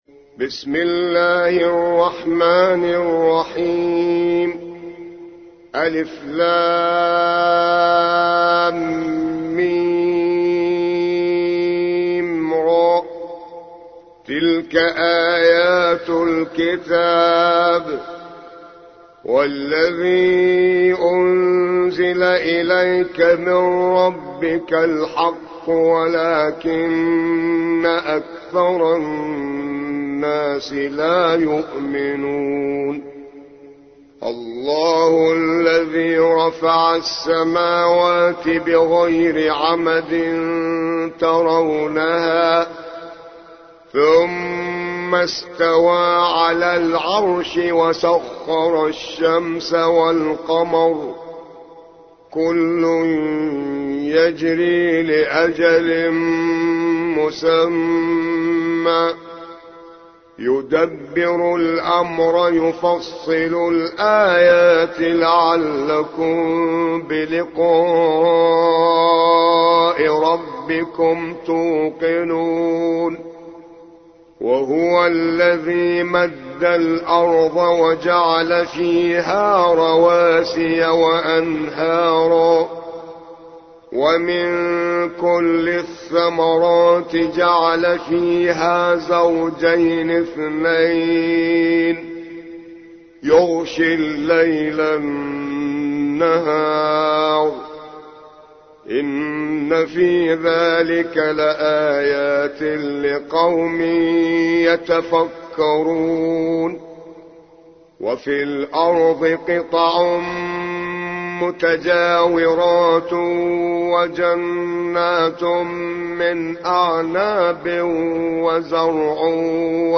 13. سورة الرعد / القارئ